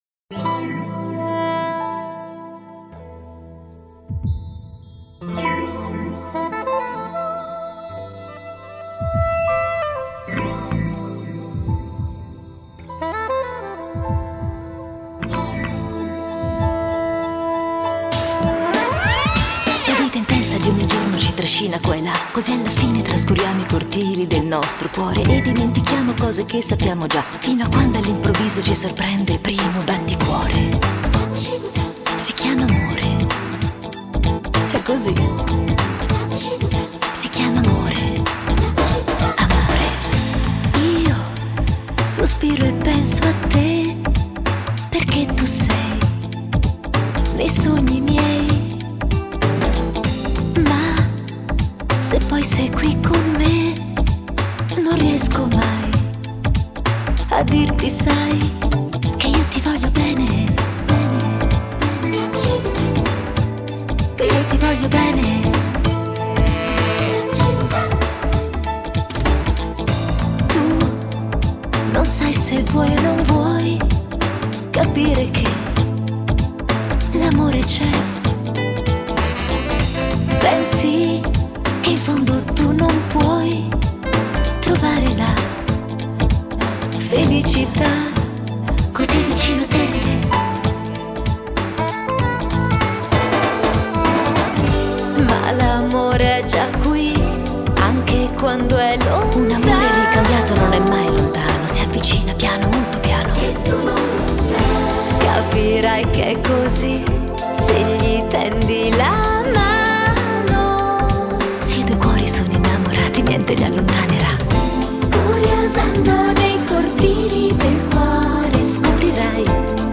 sigla italiana